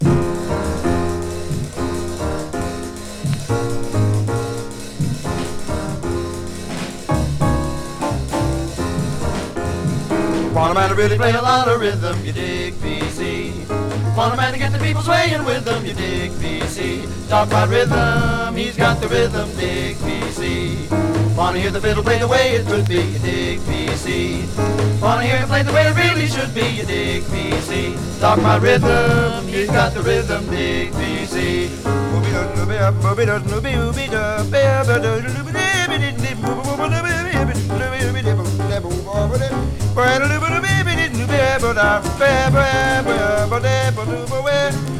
Jazz　USA　12inchレコード　33rpm　Mono